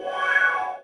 Index of /App/sound/monster/ice_snow_monster
fall_1.wav